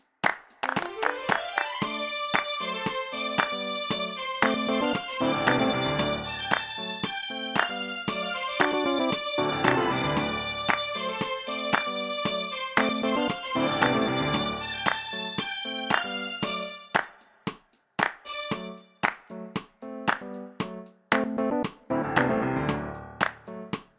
no kit bass or guitars Disco 3:41 Buy £1.50